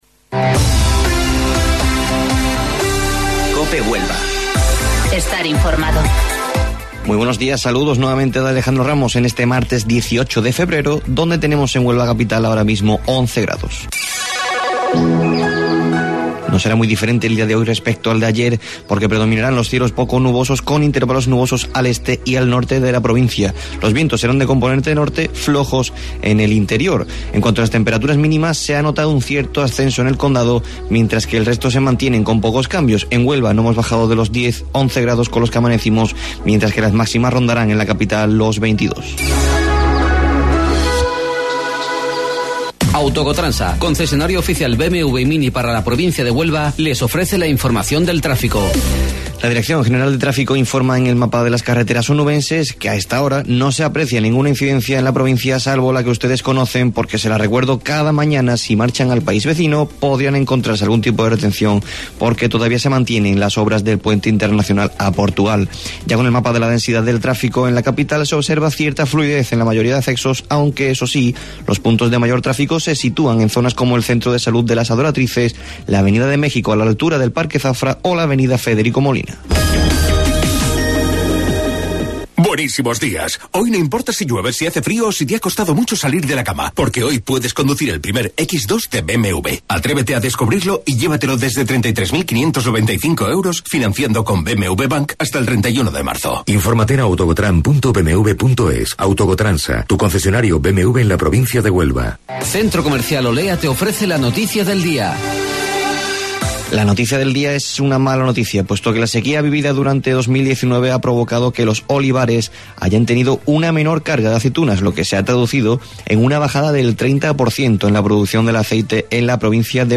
AUDIO: Informativo Local 08:25 del 18 Febrero